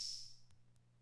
Index of /90_sSampleCDs/300 Drum Machines/Conn Min-O-Matic Rhythm/Conn Min-O-Matic Rhythm Ableton Project/Samples/Imported
HH.wav